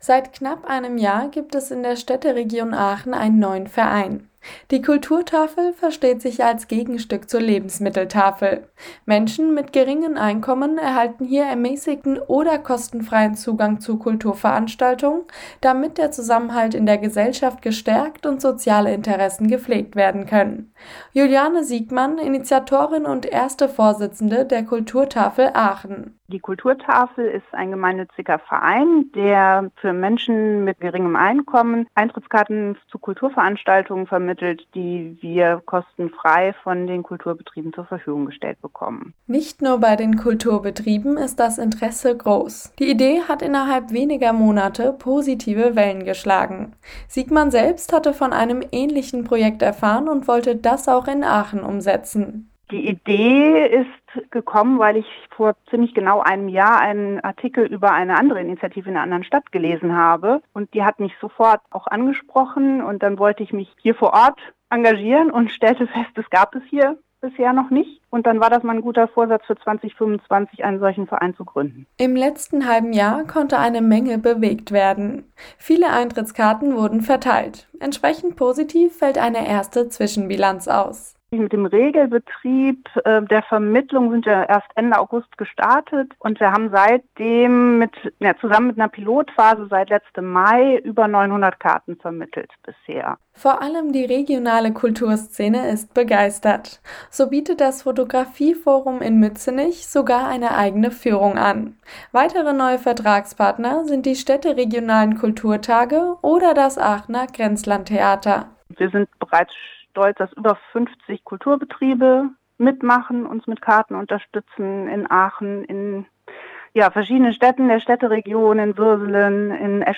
5-minütiges Radiointerview  zur Kulturtafel.